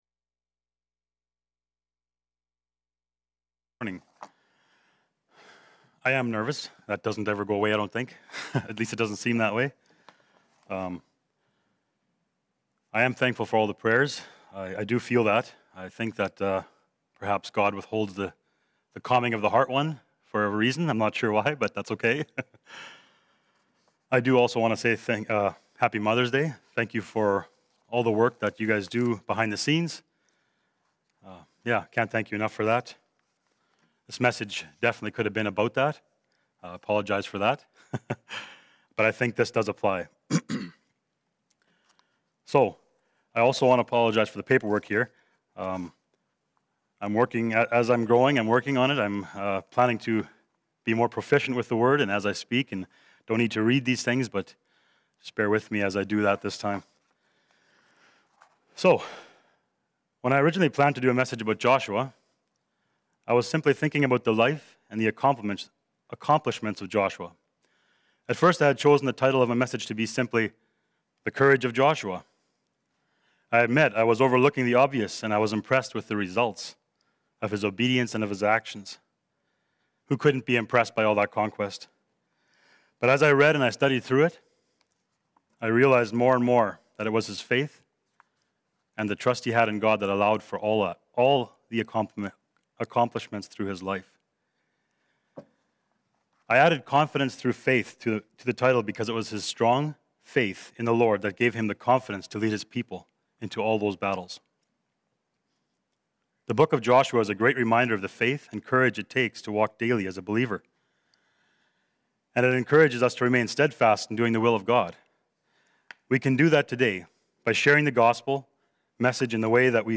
Passage: Proverbs 3:1-6 Service Type: Sunday Morning « The Good News Pushes On What Is The Wrath Of God That Is Being Revealed